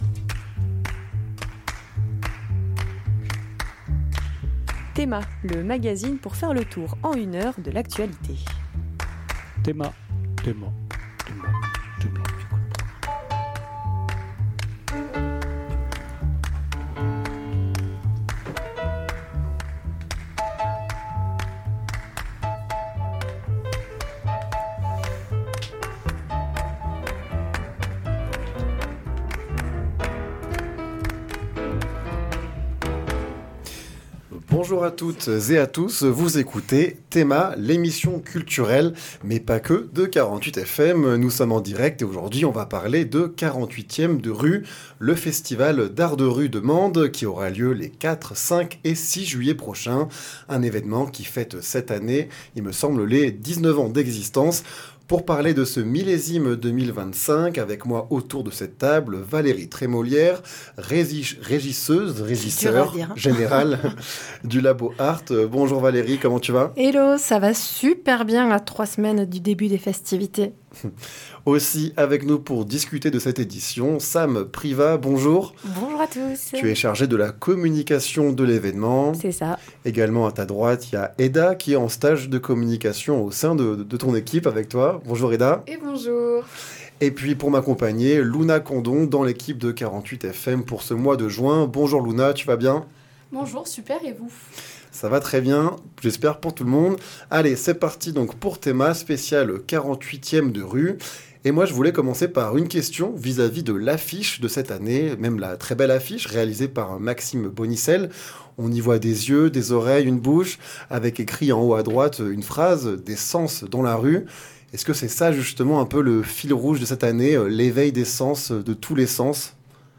Emission du mardi 17 mai 2025 en direct de 48FM